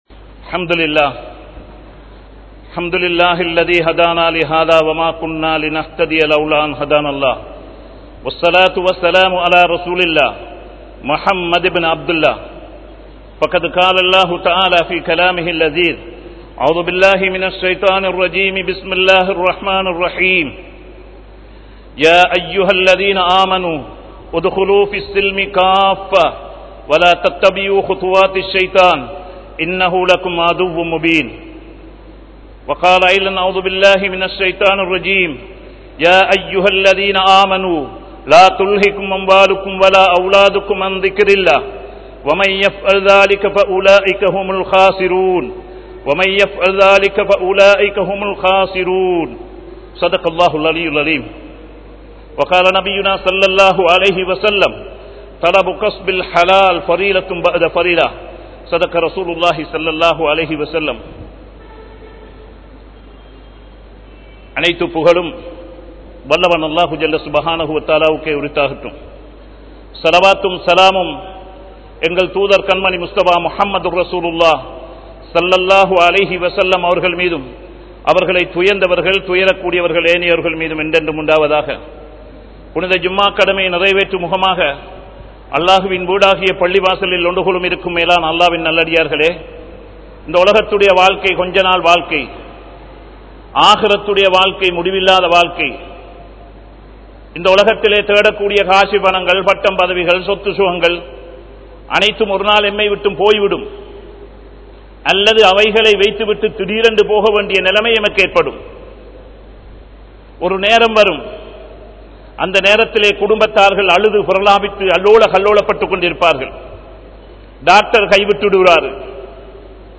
Sirantha Viyafaarien Thanmaihal (சிறந்த வியாபாரியின் தன்மைகள்) | Audio Bayans | All Ceylon Muslim Youth Community | Addalaichenai